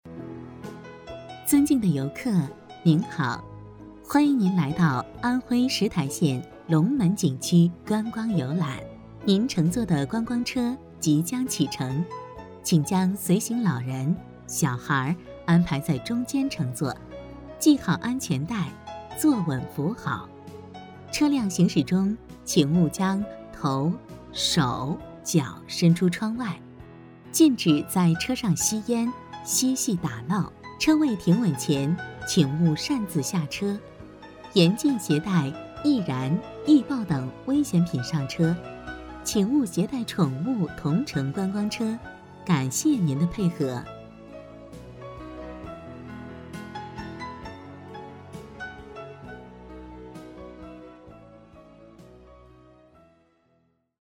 配音风格： 抒情 大气 磁性 厚重 浑厚